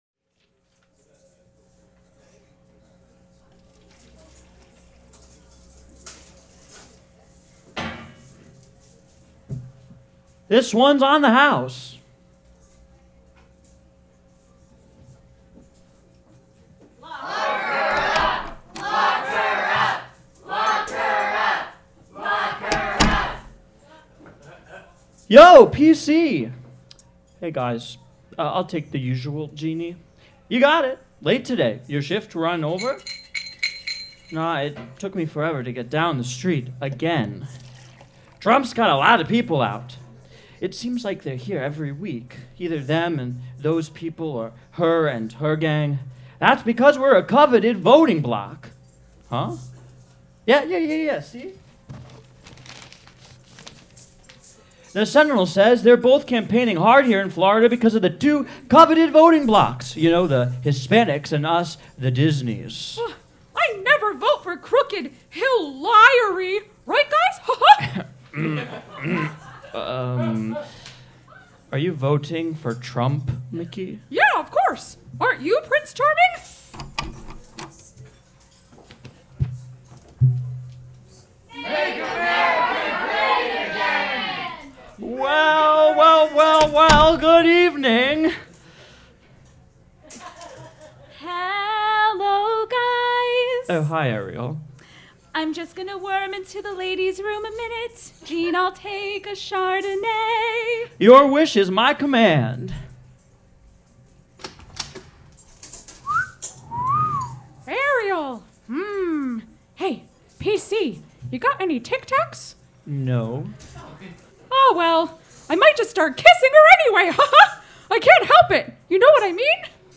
performed live for Radio COTE: The Election, November 13, 2016 at Jimmy’s No. 43